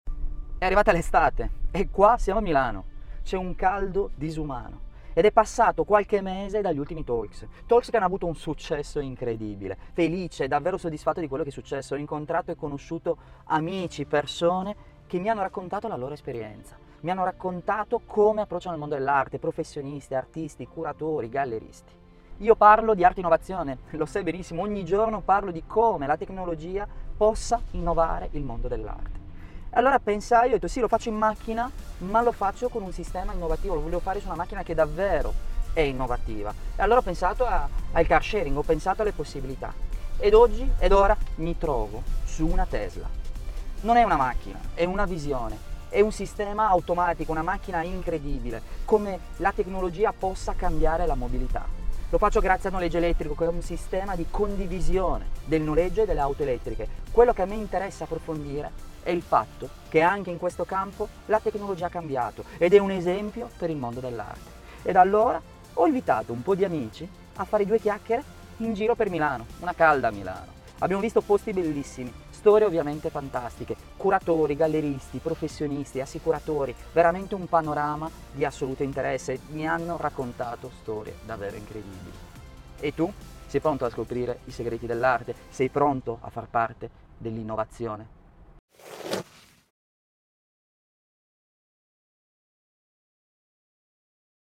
Le interviste ai professionisti dell’arte
Due chiacchiere in viaggio insieme sull’auto più green e incredibile del momento, una vera e propria opera d’arte su quattro ruote.